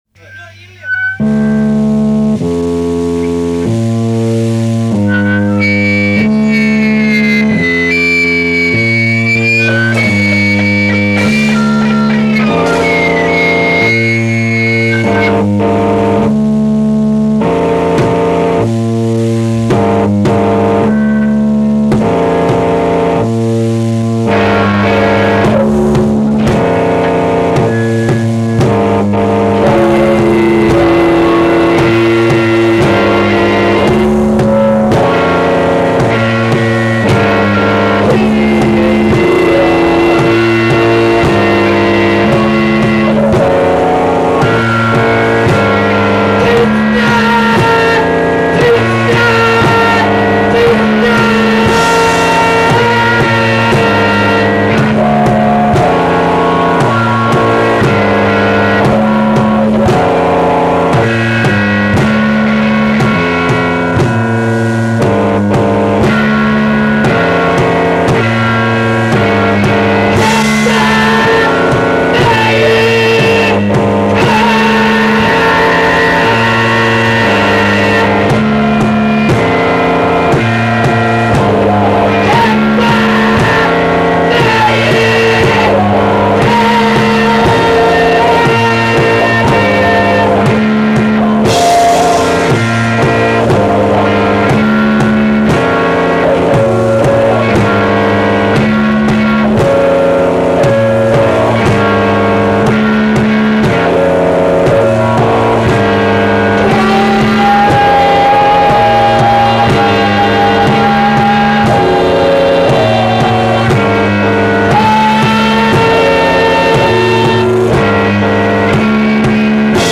Groupe suedois formé en 1979